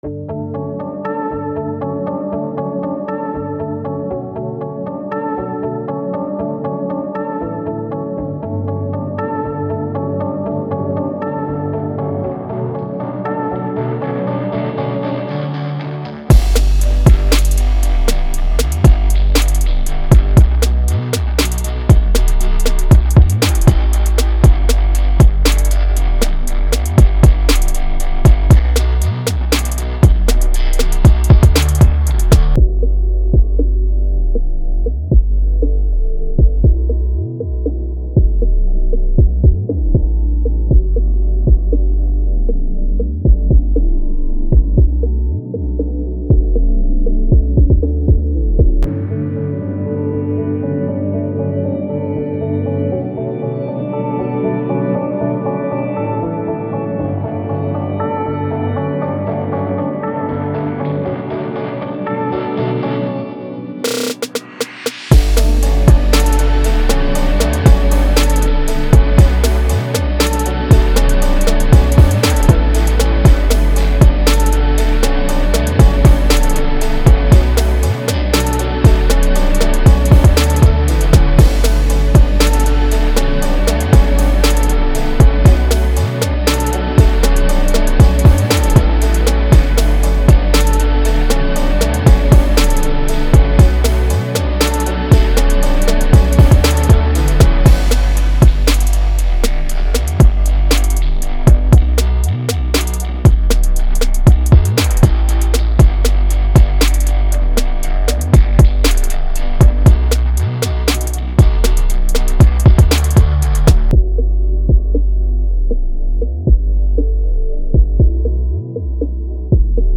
Pop
B Min